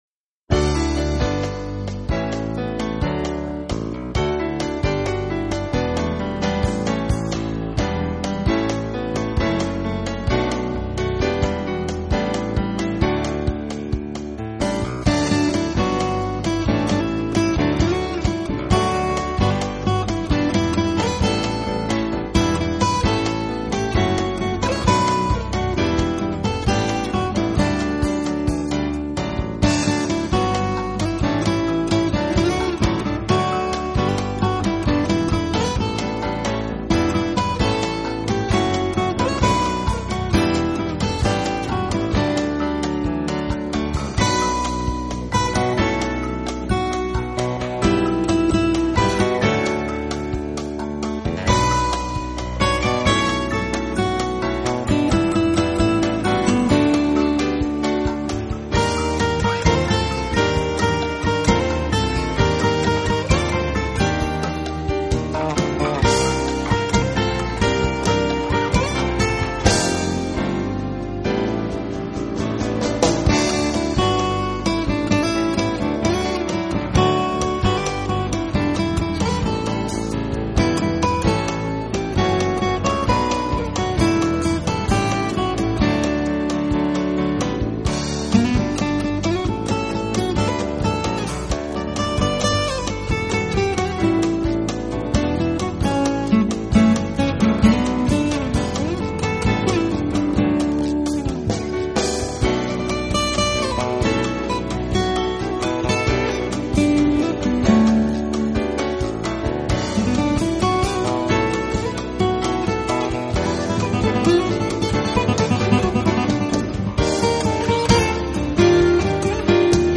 【爵士吉他】
的声学木吉他，在曲风上又非常的类似，和流行音乐的结合同样的热衷。